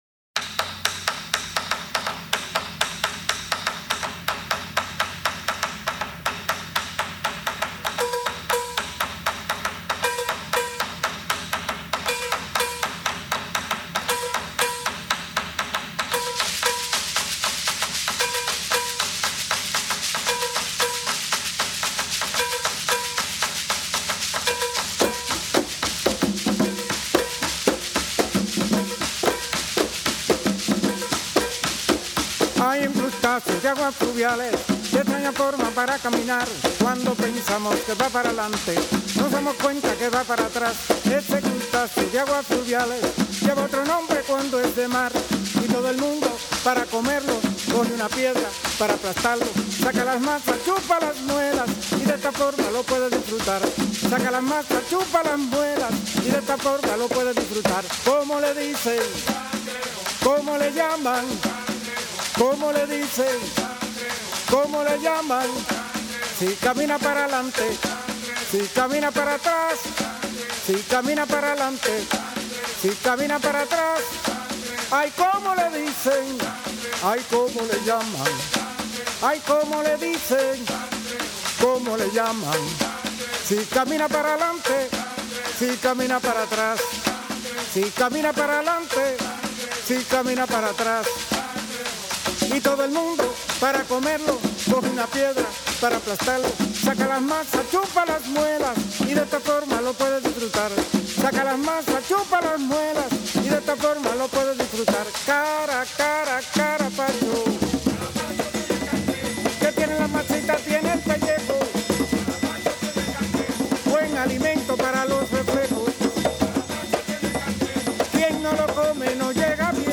Хорошим примером тому могла бы послужить деревенская песенка «El cangrejo» («Краб»):
rumbitascubanasledicencangrejo.mp3